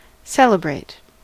Ääntäminen
IPA : /ˈsɛl.ɪ.bɹeɪt/